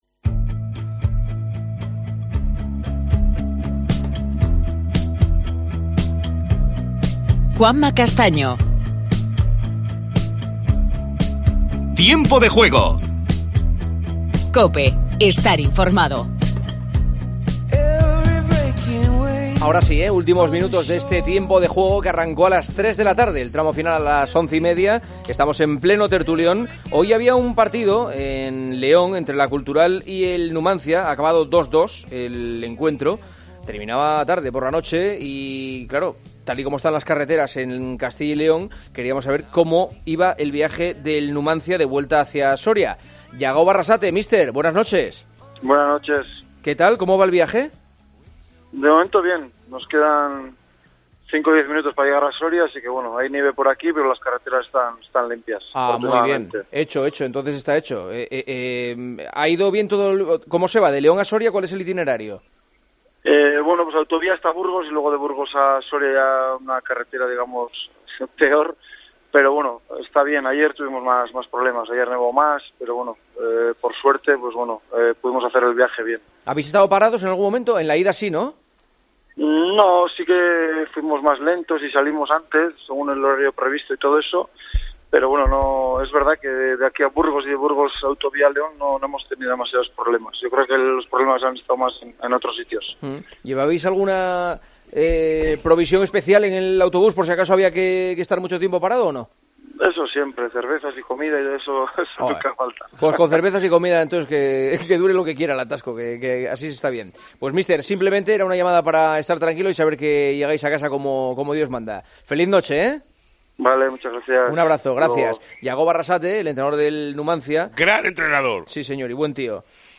Entrevista con el entrenador del Numancia, Jagoba Arrasate, en su vuelta por carretera desde León a Soria....
Las preguntas de los oyentes.